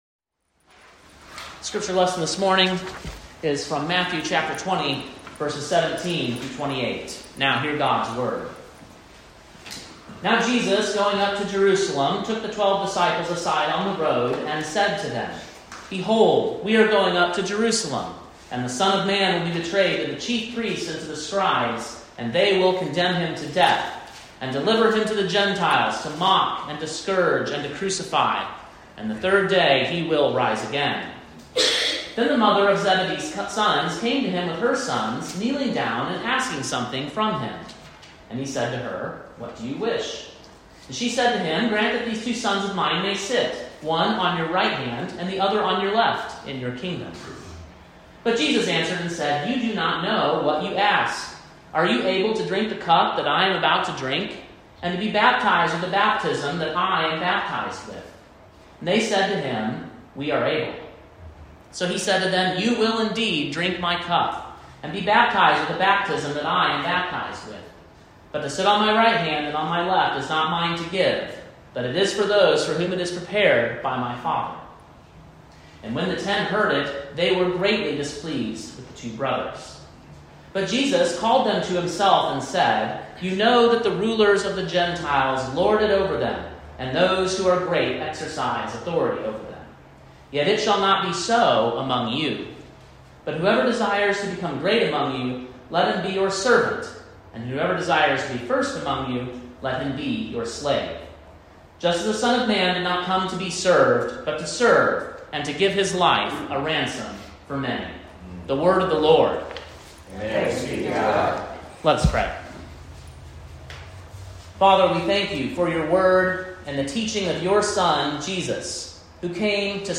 Sermon preached on March 10, 2024, at King’s Cross Reformed, Columbia, TN.